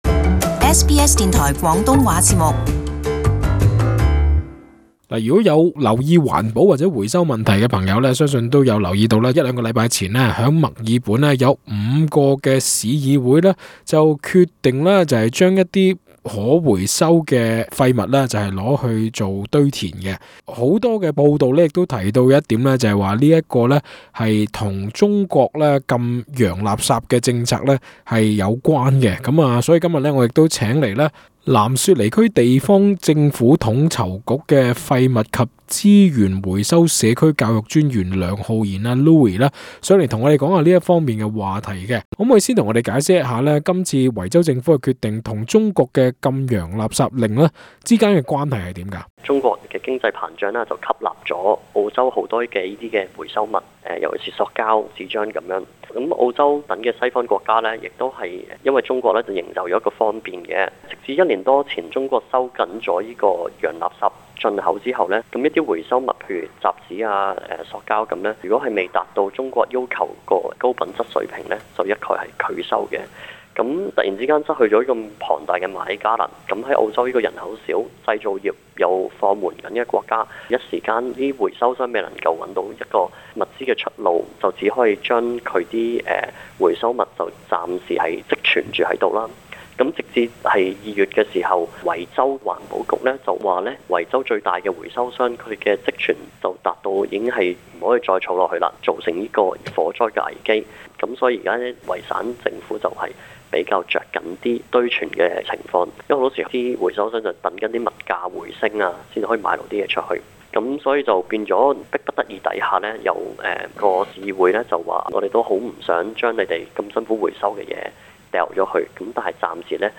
【社區專訪】澳洲回收危機會加劇嗎？